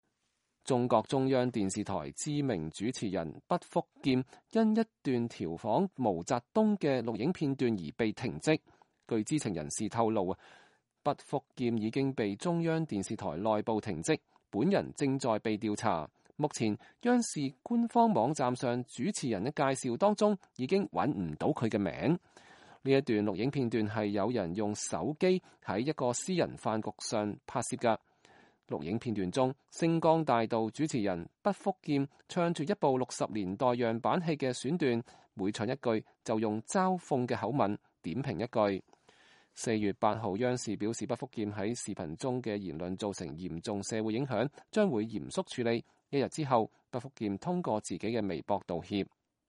這段視頻是有人用手機在一個私人飯局上拍攝的。視頻中，《星光大道》主持人畢福劍唱著一部60年代樣板戲的選段，每唱一句就用嘲諷的口吻點評一句。